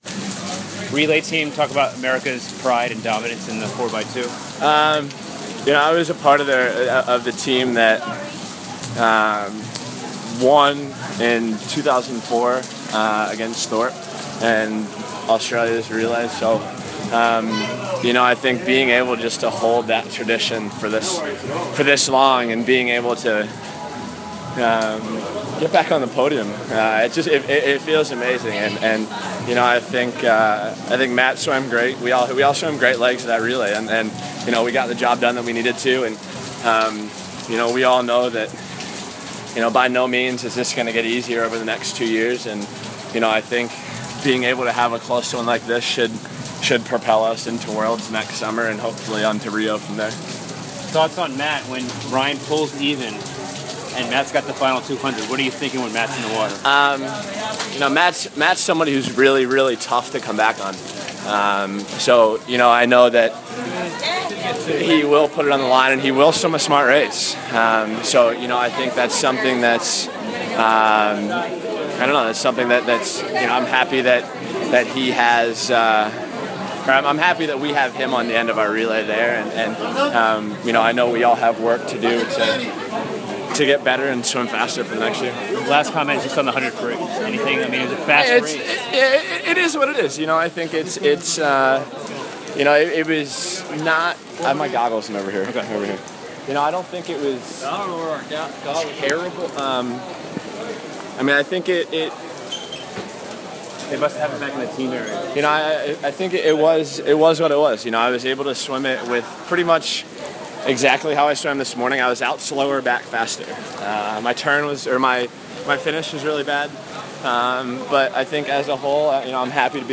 Afterward, three of the members of the winning relay offered some thoughts on the swim via audio recordings by USA Swimming: